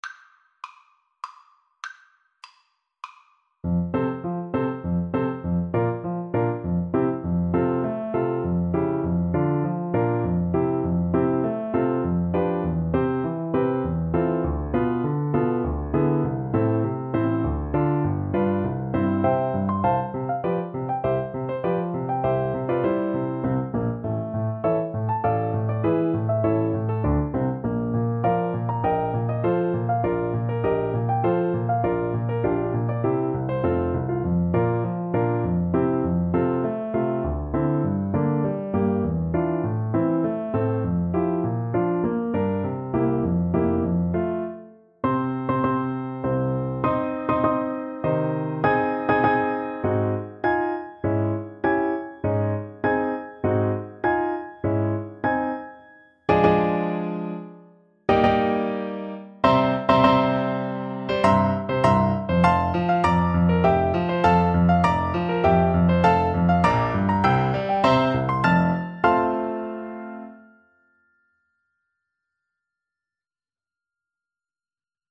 F major (Sounding Pitch) G major (Clarinet in Bb) (View more F major Music for Clarinet )
3/4 (View more 3/4 Music)
~ = 100 Tempo di Menuetto
Classical (View more Classical Clarinet Music)